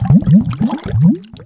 BUBBLES3.WAV